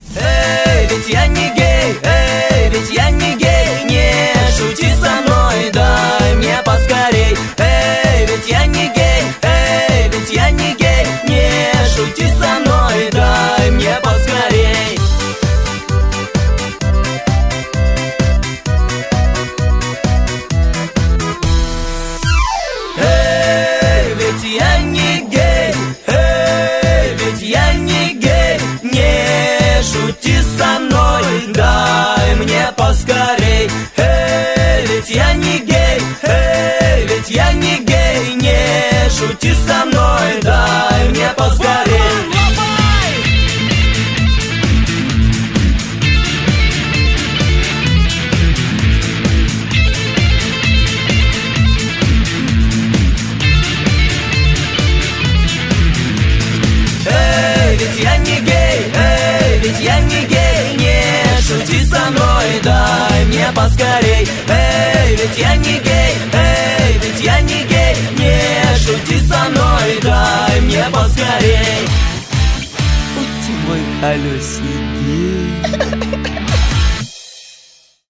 веселые
Гармошка
смех